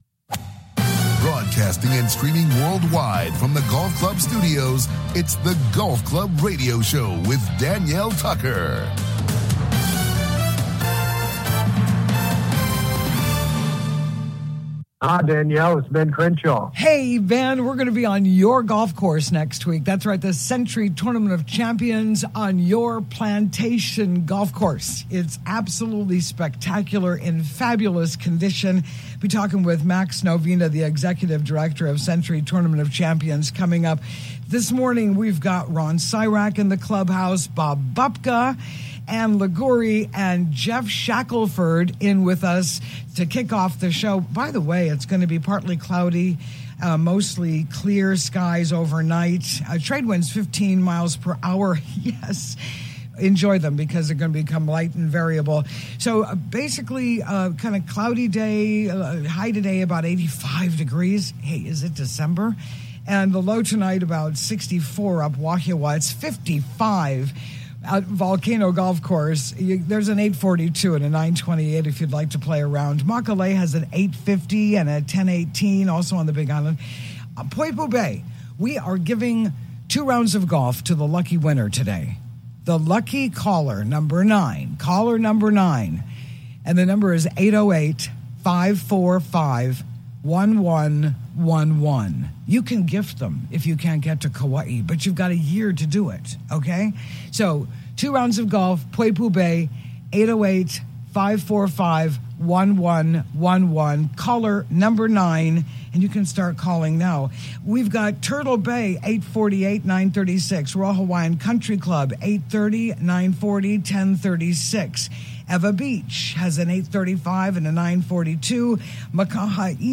The Golf Club Radio Show� broadcasting world-wide from Hawaii.